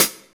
Hat (29).wav